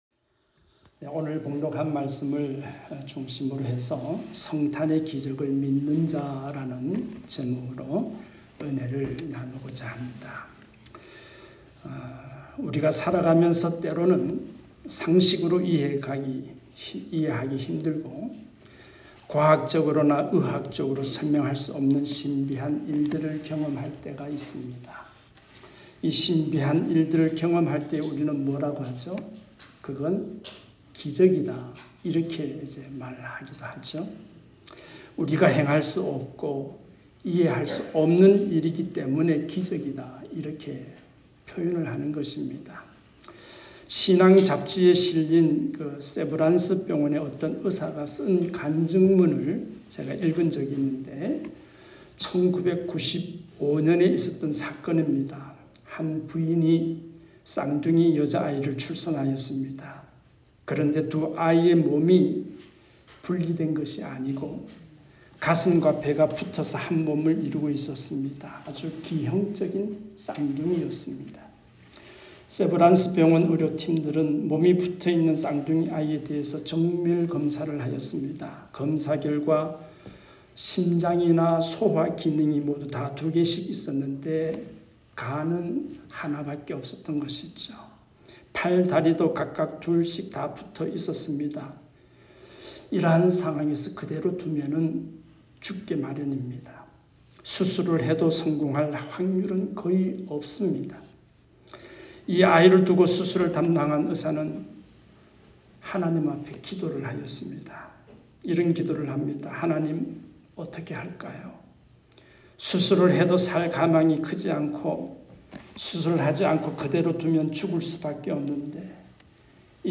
성탄의 기적을 믿는자 ( 마1:18-25 ) 말씀